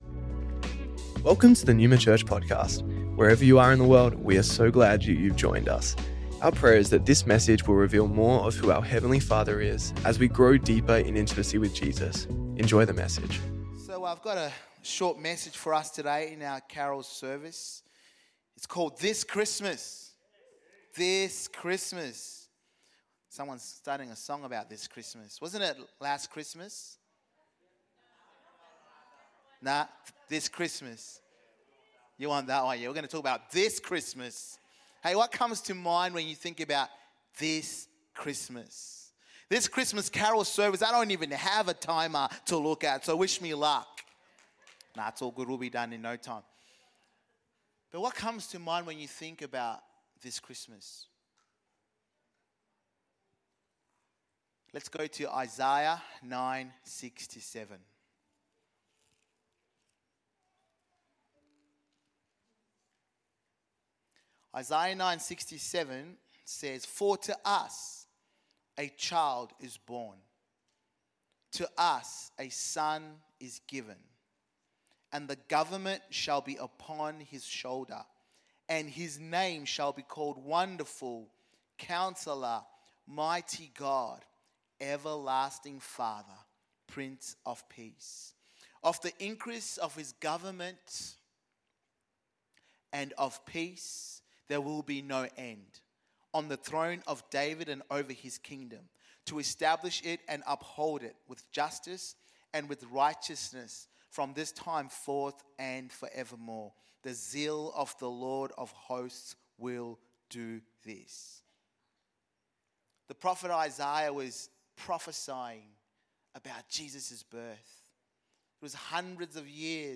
Neuma Church Melbourne South Originally Recorded at the 10AM Service on Sunday 8th December 2024